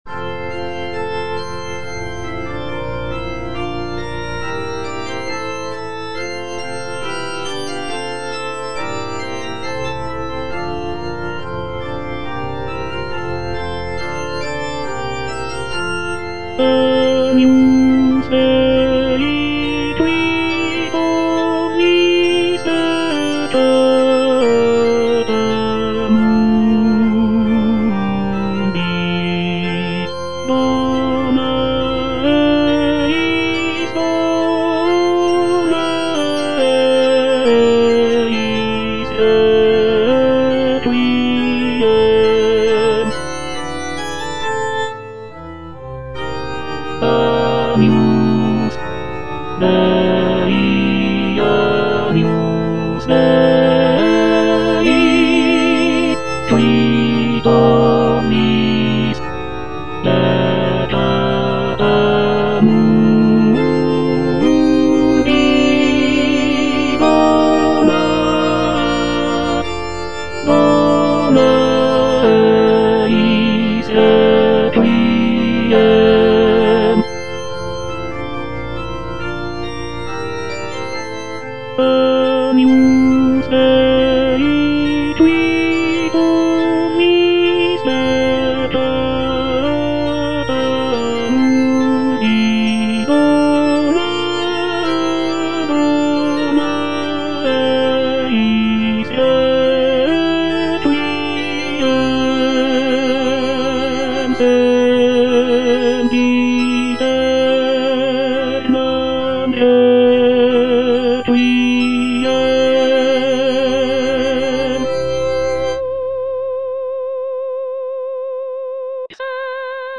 G. FAURÉ - REQUIEM OP.48 (VERSION WITH A SMALLER ORCHESTRA) Agnus Dei (tenor II) (Emphasised voice and other voices) Ads stop:
This version features a reduced orchestra with only a few instrumental sections, giving the work a more chamber-like quality.